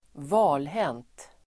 Ladda ner uttalet
valhänt adjektiv, fumbling Uttal: [²v'a:lhen:t] Böjningar: valhänt, valhänta Synonymer: fumlig, klumpig, trevande Definition: stel i fingrarna (av kyla etc); fumlig (stiff-fingered (from the cold etc.) clumsy, awkward)